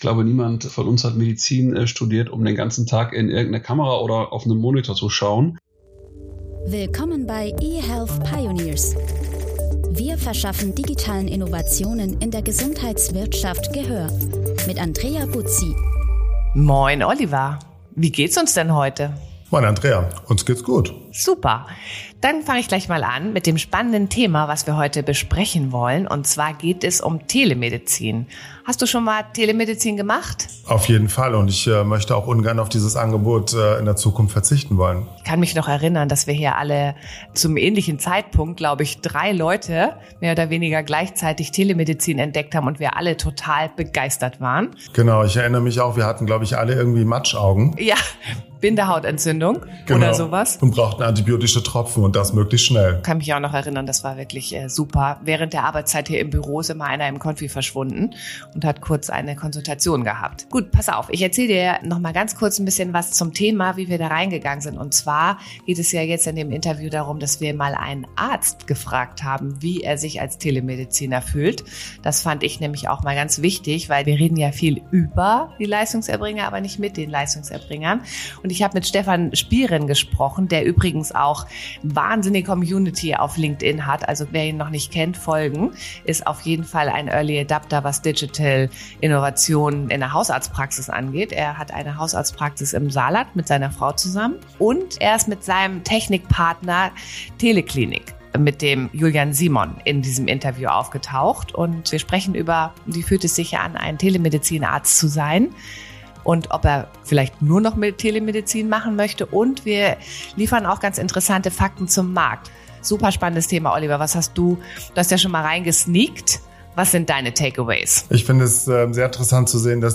Ihre Interview-Gäste sind Gründer, Innovatoren und Akteure im E-Health-Bereich. Der Podcast will den Dialog zwischen Unternehmen, Patienten und Ärzten anregen und digitalen Gesundheitsprodukten Gehör verschaffen.